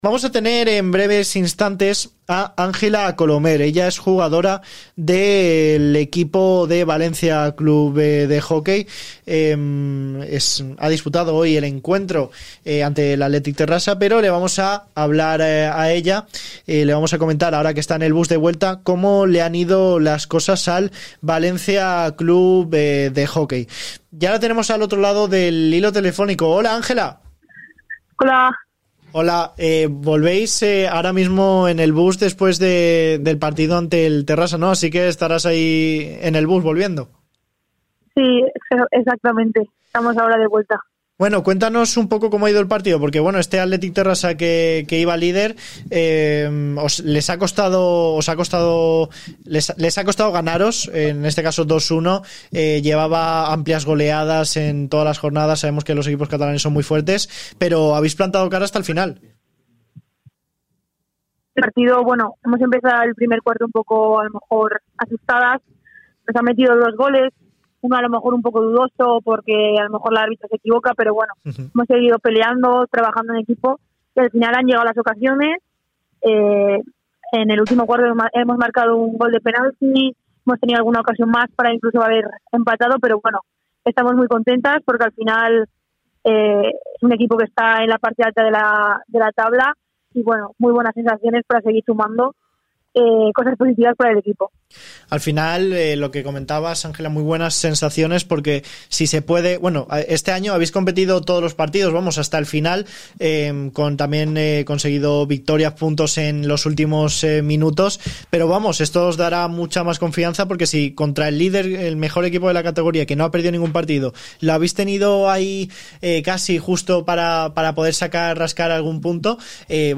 en el bus de vuelta a Valencia
Entrevistas